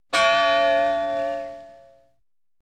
Church Single Bell Sound Effect Free Download
Church Single Bell